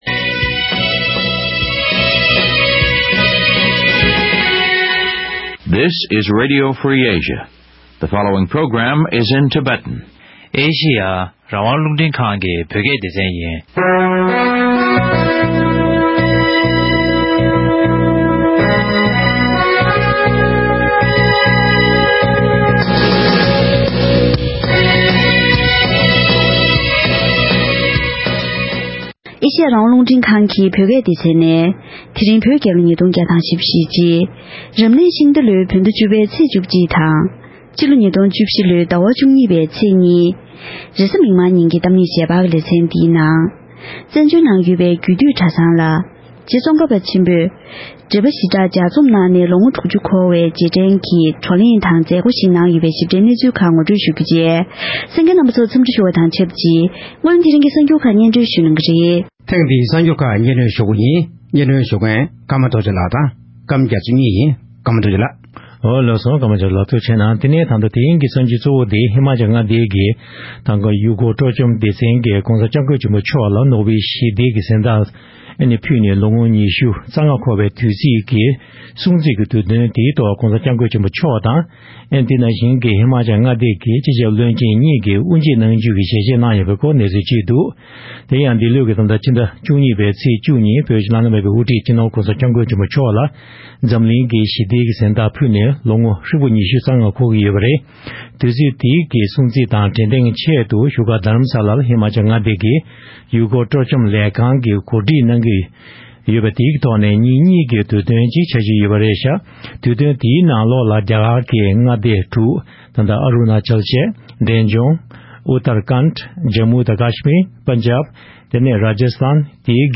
བགྲོ་གླེང་ཐོག་མཉམ་ཞུགས་གནང་མཁན་དགེ་བཤེས་ཁག་ཅིག